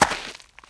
High Quality Footsteps
STEPS Gravel, Walk 05.wav